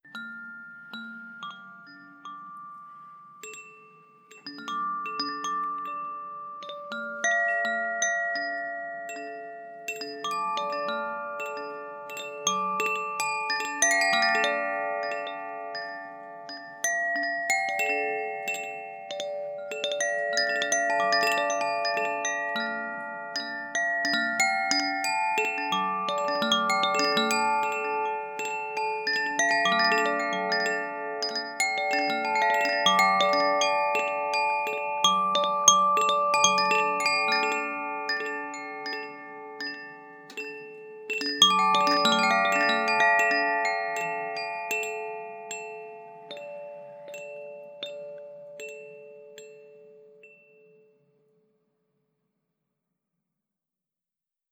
• koshi wind chimes aqua sounds.wav
koshi_wind_chimes_aqua_sounds_PFl.wav